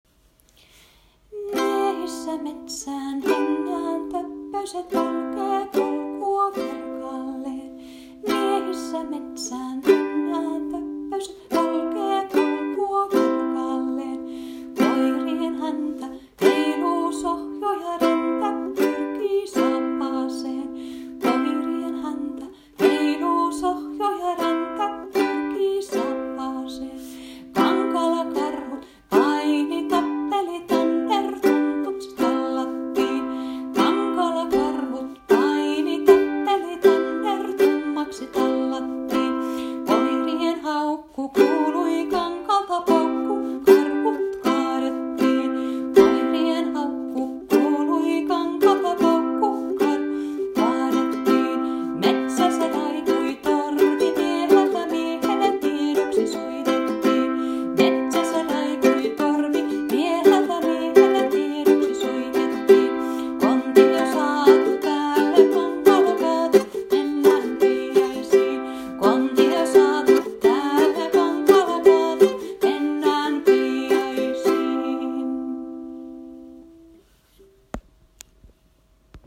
Karhunpeijaispolska ukulele.m4a